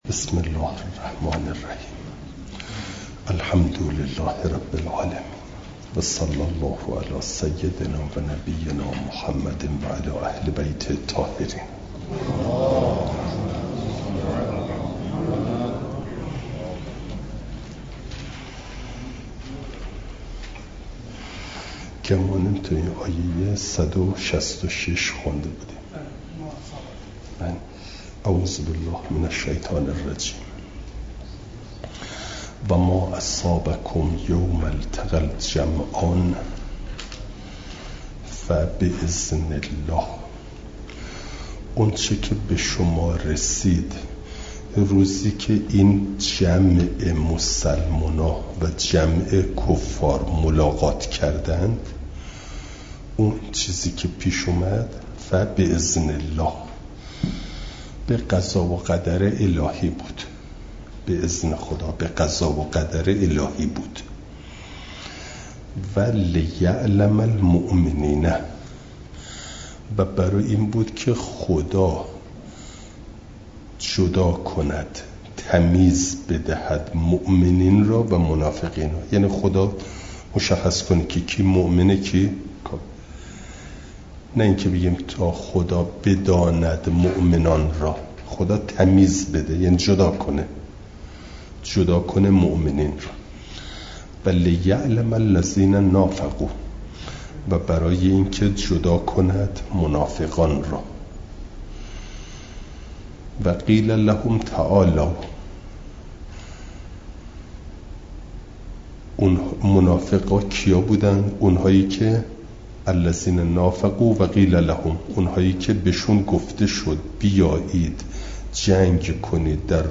جلسه سیصد و بیست و دوم درس تفسیر مجمع البیان